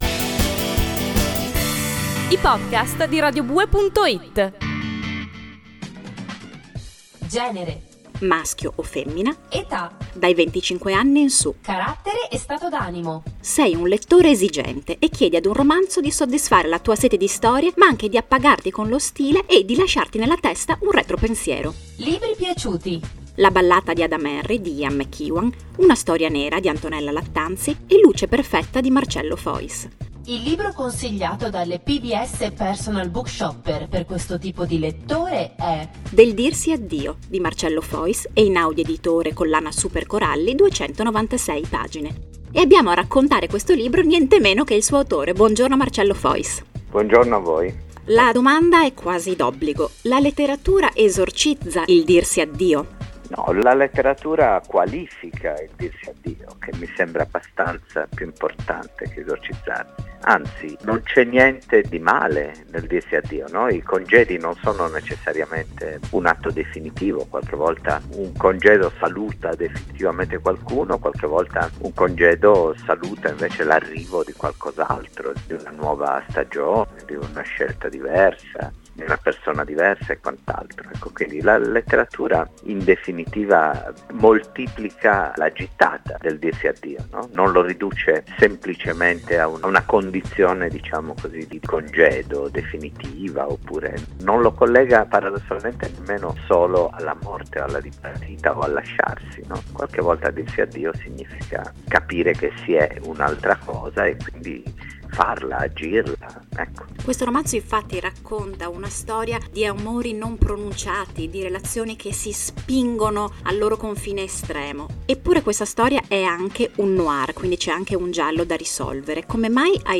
Del dirsi addio, intervista a Marcello Fois